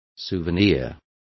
Complete with pronunciation of the translation of souvenir.